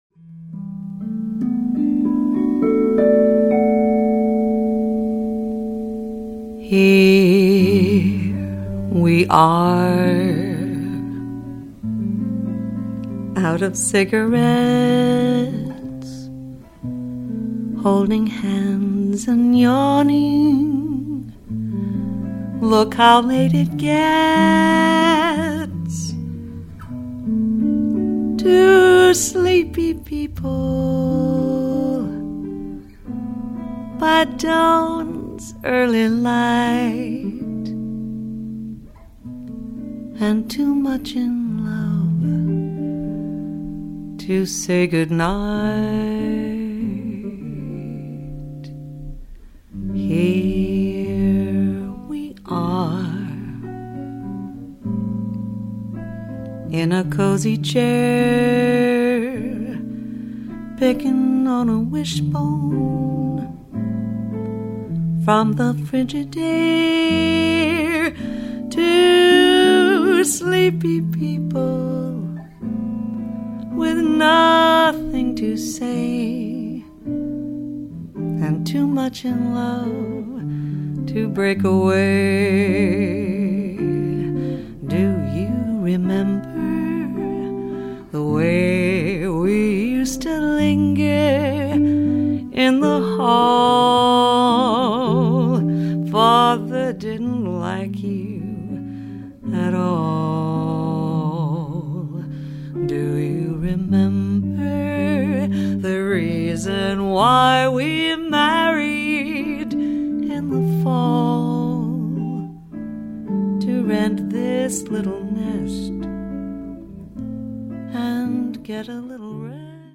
"This is a demo-quality disc.